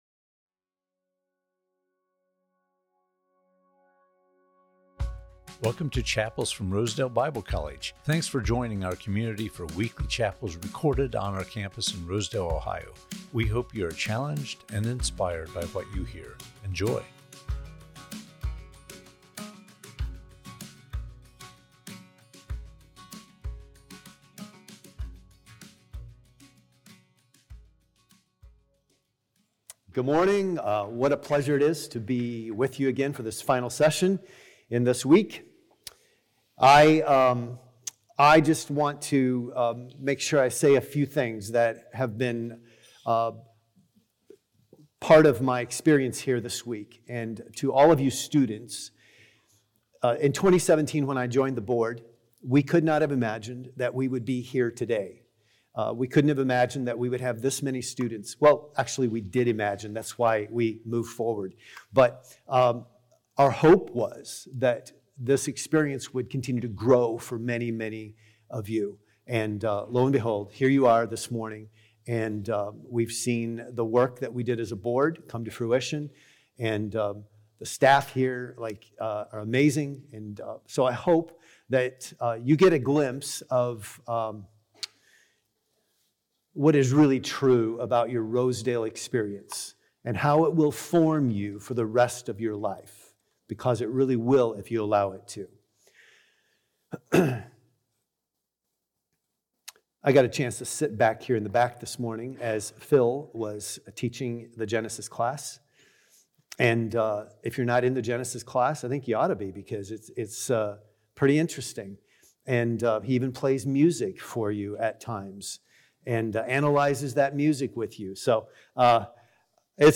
Chapels from Rosedale Bible College Strength - Flex = Power